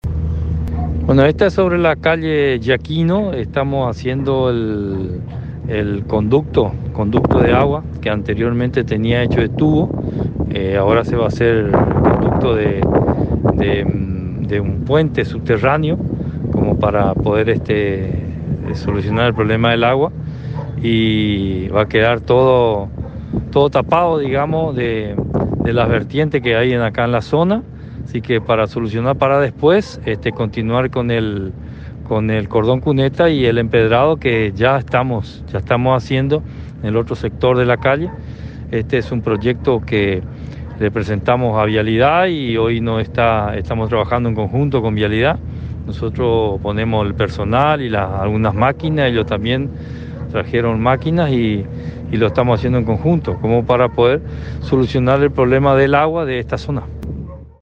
Audio: intendente Carlos Pernigotti